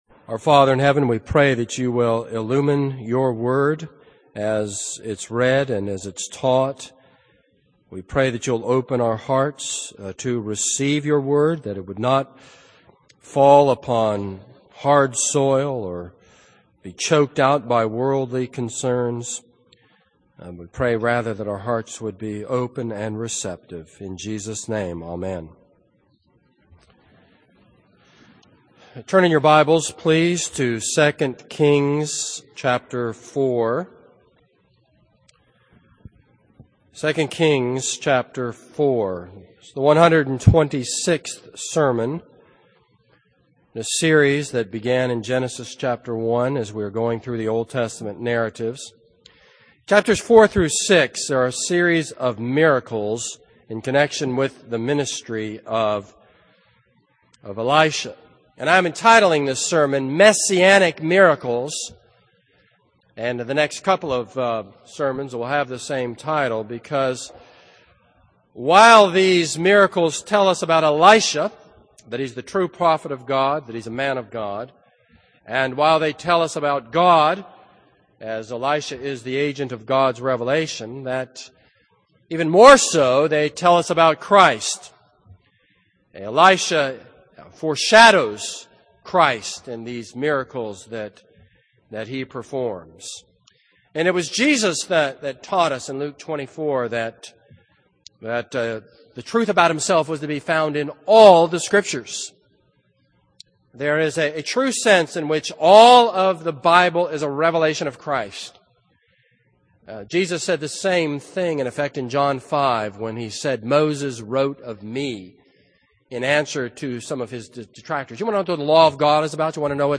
This is a sermon on 2 Kings 4.